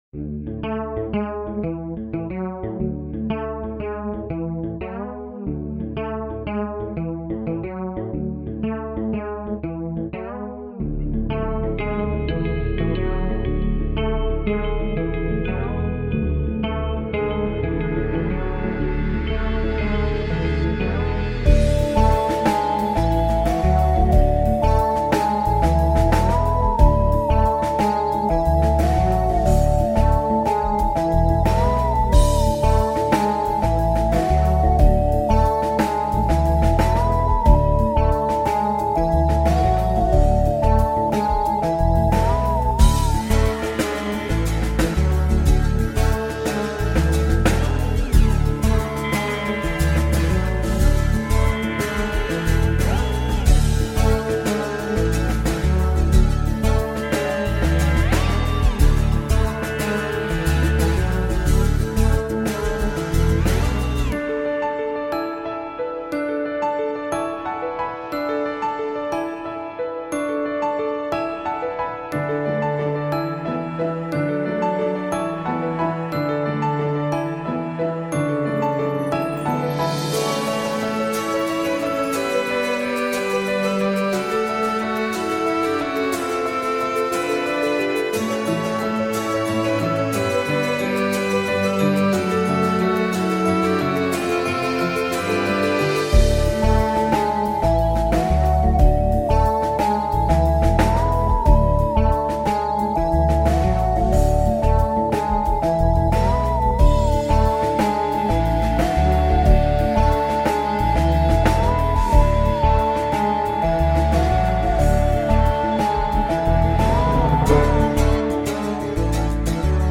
An original instrumental by me